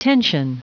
Prononciation du mot tension en anglais (fichier audio)
Prononciation du mot : tension